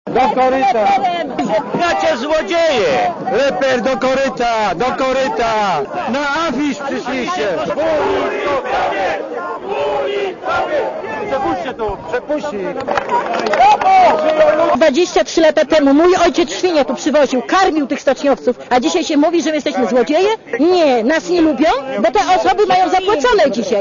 awantura.mp3